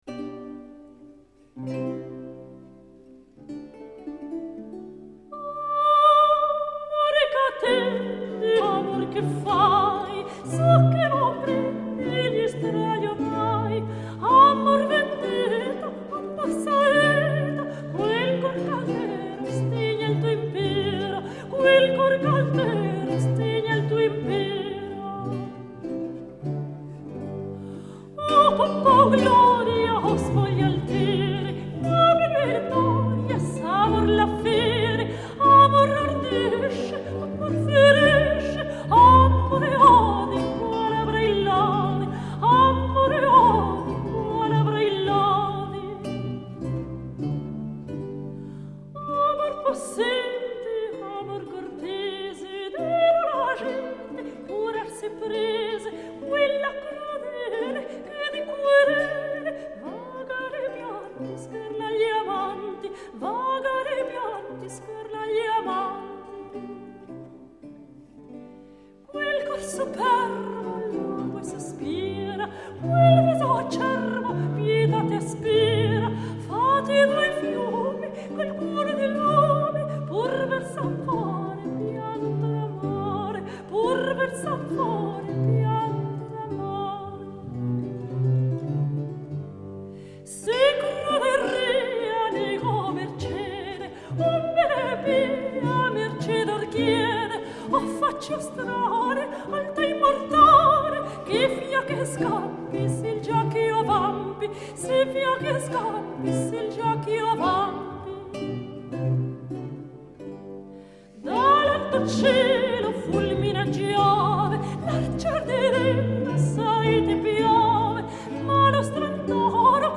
她嗓音清澈、灵活，以其独特的方式演绎早期声乐作品，特别是西班牙和卡塔罗尼亚作品，她多次获得国际大奖。
Gesang
Laute
Barockgitarre
Viola da Gamba
Harfe